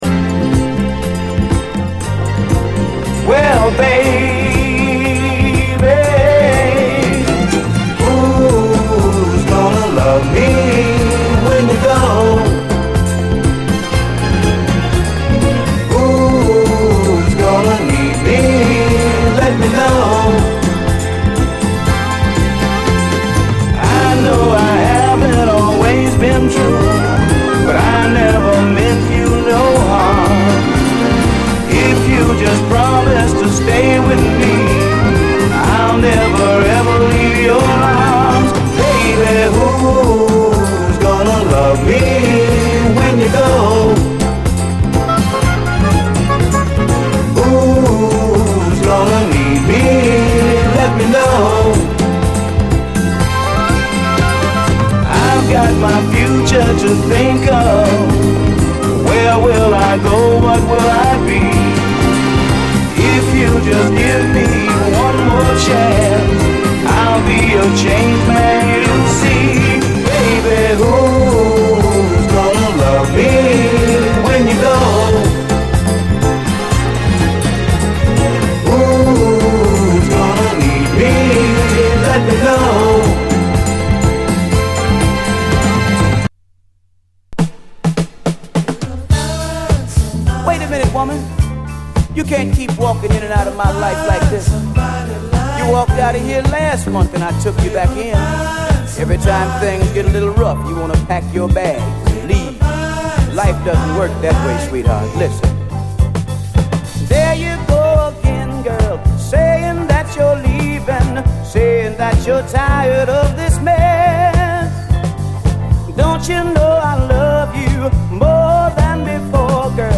SOUL, 70's～ SOUL
後半にはパーカッシヴなドラム・ブレイクも！